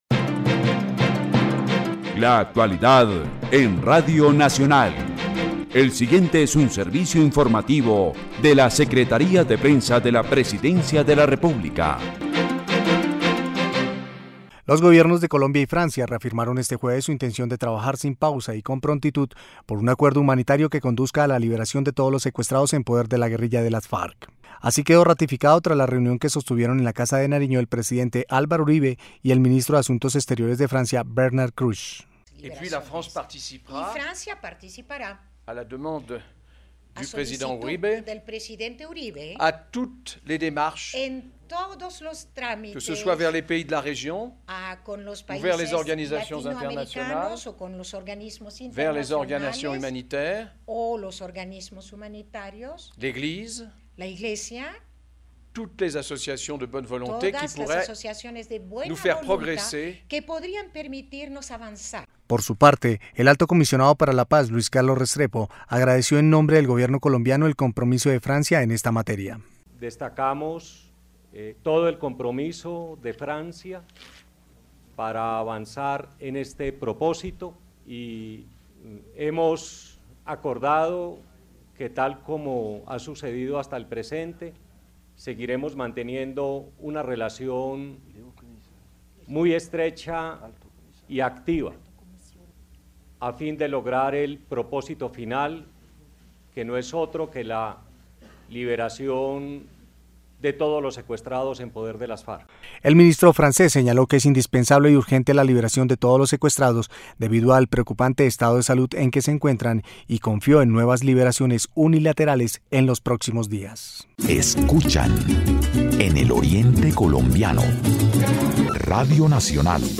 La Secretaría de Prensa de la Presidencia presenta a sus usuarios un nuevo servicio: El Boletín de Noticias, que se emite de lunes a viernes, cada tres horas, por la Radio Nacional de Colombia, en las frecuencias 570 AM y 95.9 FM.